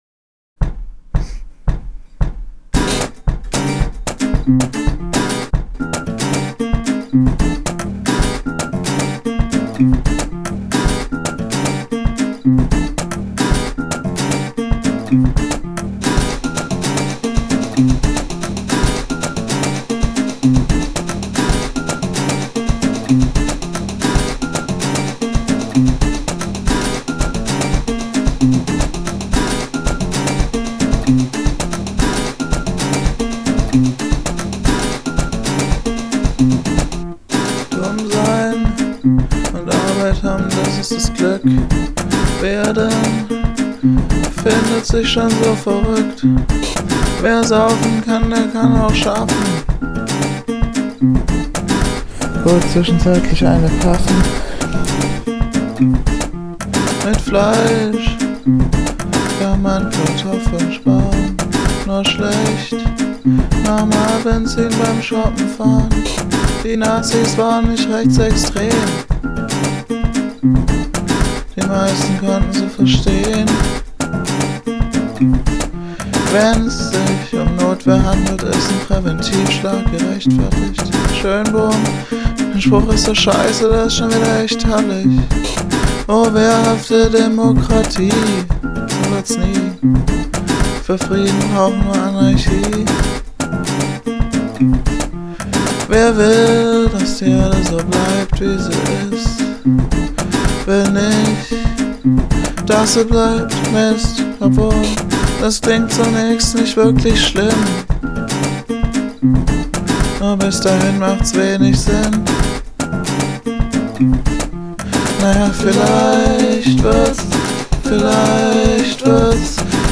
Demoversion vom 16.4.2006